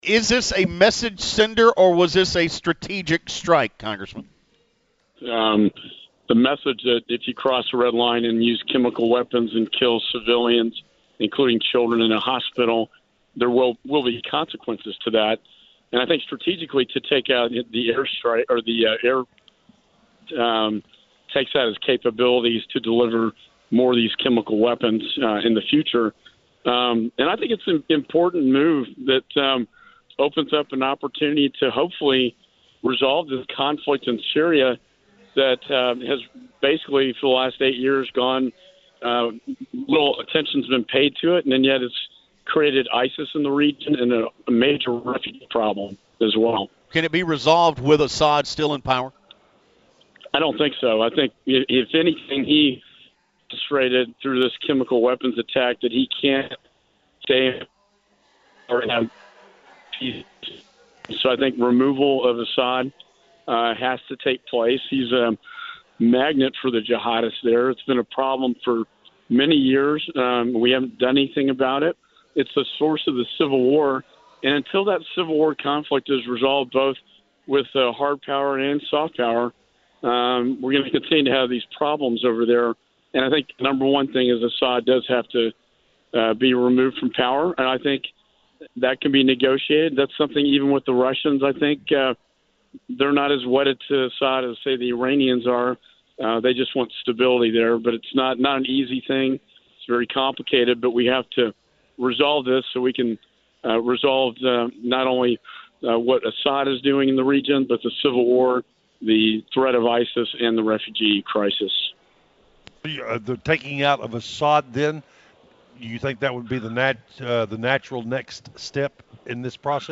Also joining the WBAP Morning News Friday morning was Congressman Michael McCaul, who discussed whether or not the attack was a “message sender” or a strategic strike, and also what he thinks the next step in Syria should be.